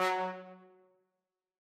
brass.ogg